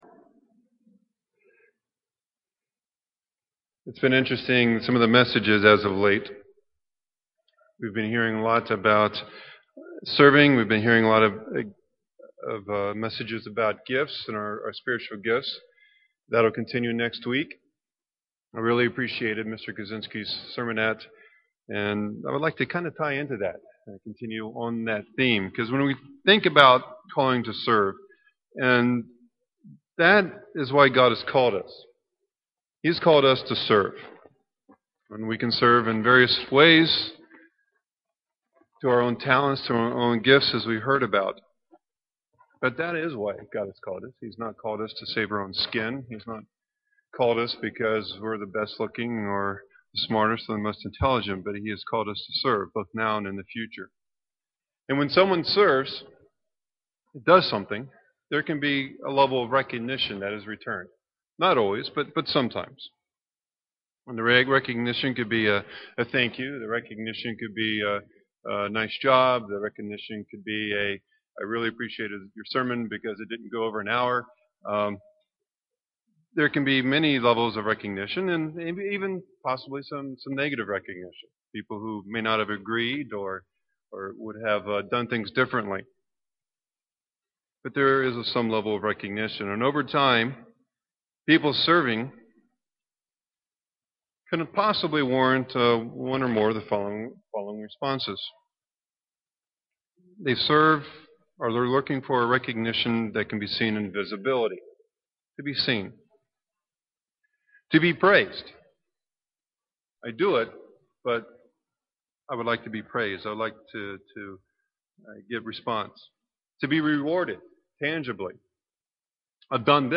Given in Milwaukee, WI
UCG Sermon Studying the bible?